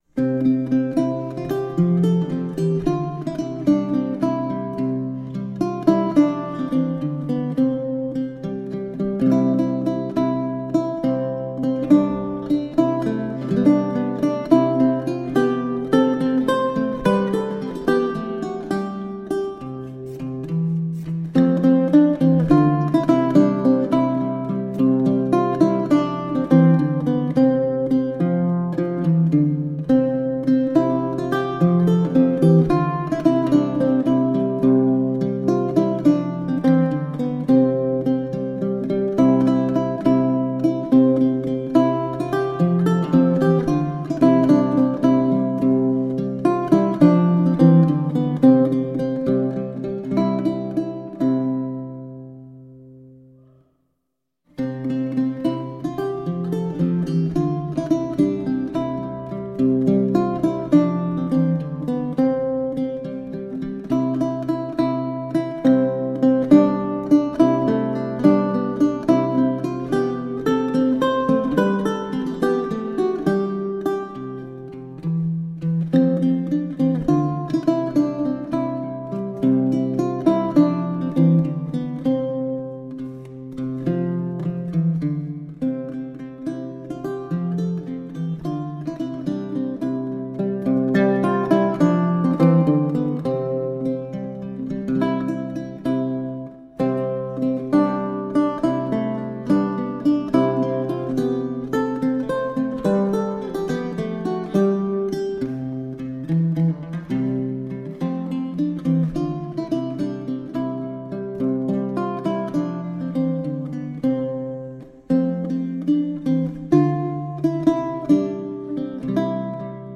Late-medieval vocal and instrumental music.
Lute